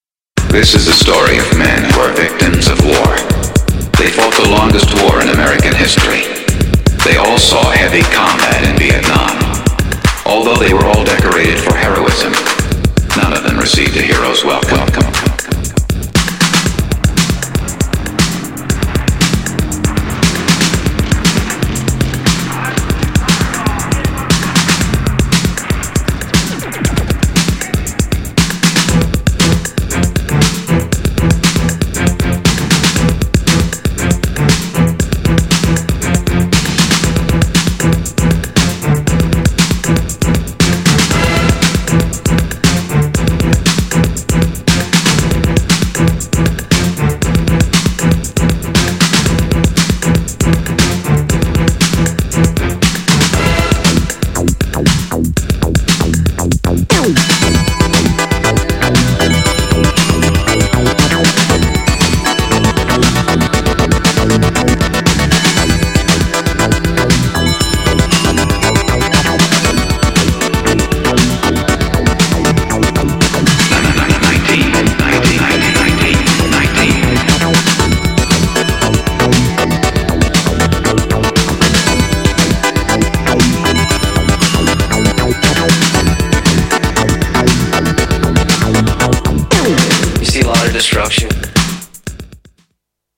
GENRE Dance Classic
BPM 116〜120BPM
# EURO_DISCO # FUSION # INSTRUMENTAL # JAZZY
# エレクトロ # ニューウェーブ # バレアリック # ピアニスト